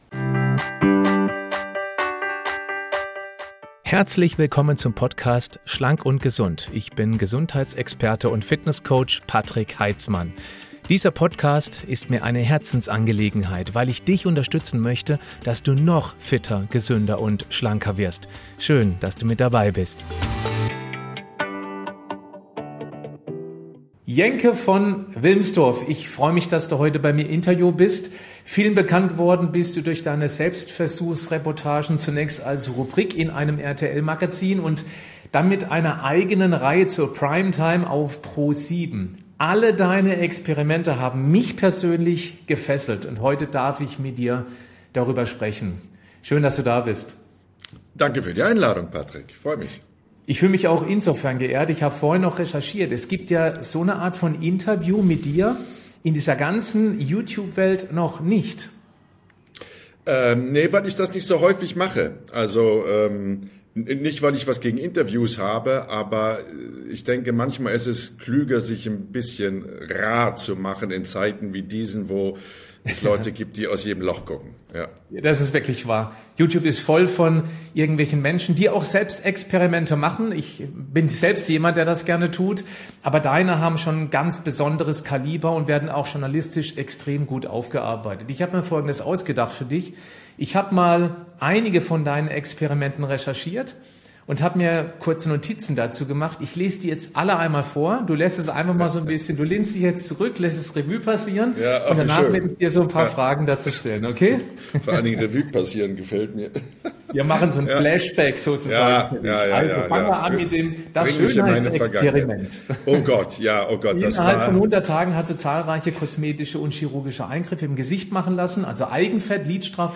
In diesem faszinierenden Interview treffe ich den mutigen Journalisten und Moderator Jenke von Wilmsdorff, der für seine extremen Selbstversuche bekannt ist. Jenke geht in seinen Experimenten an die Grenzen der physischen und psychischen Gesundheit, um zu testen, was der menschliche Körper und Geist wirklich aushalten können und um zu sensibilisieren.